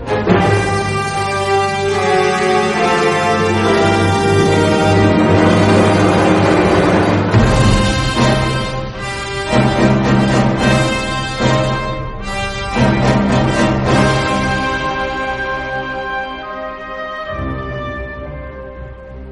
Categoria Classiche